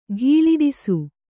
♪ ghīḷiḍisu